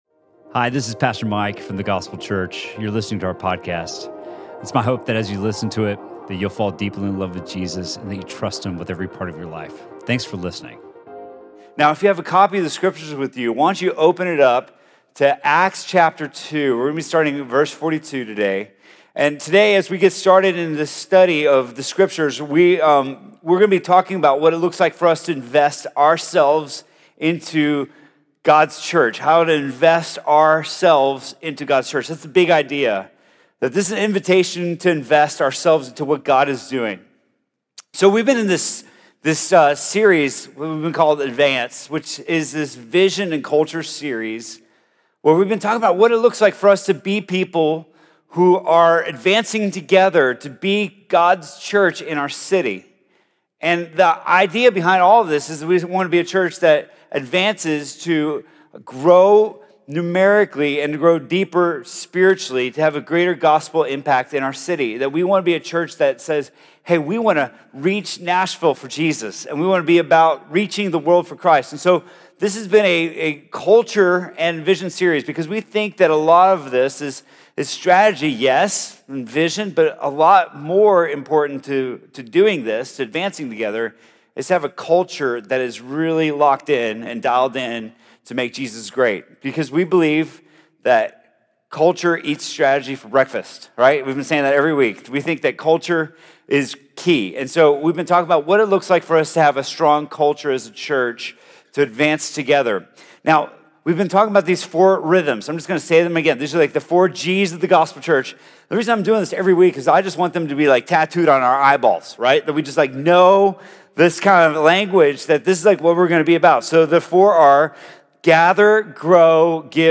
Sermon from The Gospel Church on November 3rd, 2019.